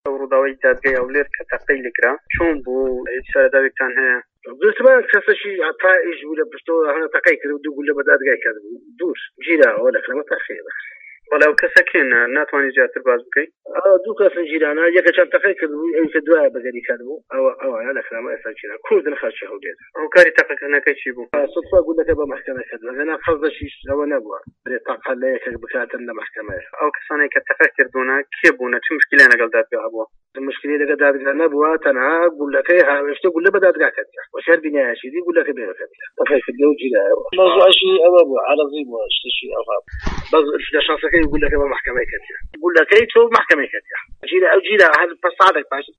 بەڕێوەبەری ئاسایشی هەولێر لە دیمانەیەکی لەگەڵ بەشی کوردی دەنگی ئەمەریکا سەبارەت بە ڕووداوەکە دەڵێت" ئەوانەی دەستگیركراون دوو كەس بوون لە پشتەوە تەقەیان كردبوو دوو گولە بەر دادگا كەوتووە و لە لای ئێمەن دەستگیركراون."
دەقی لێدوانەکەی تارق نوری بۆ دەنگی ئەمەریکا.